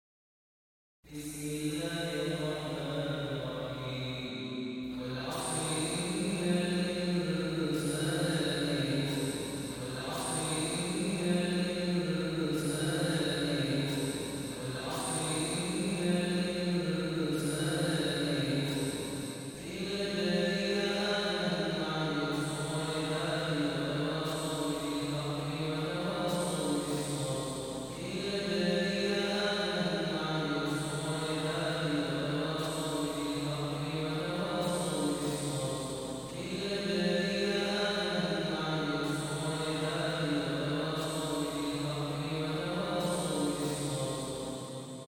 Hören Sie sich den edlen Quran Rezitation von bekannten Rezitatoren und laden Sie sich die vollständige Koran Rezitation runter